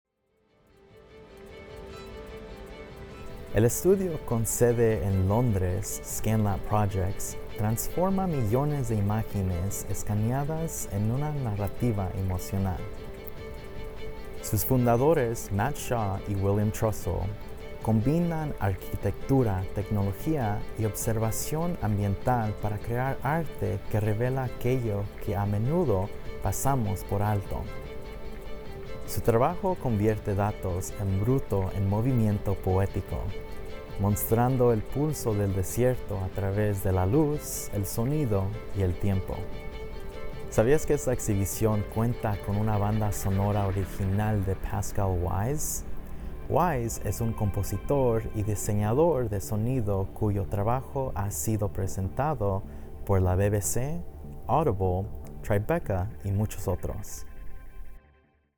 Este es un viaje sonoro autoguiado, diseñado para acompañarte mientras te desplazas entre los sitios de las instalaciones.